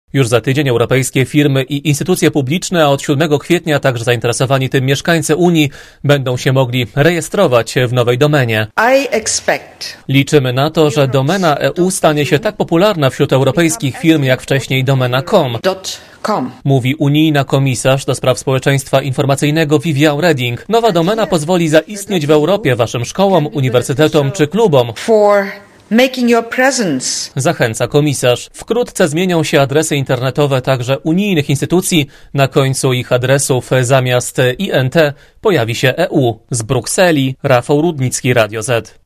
Relacja korespondenta Radia ZET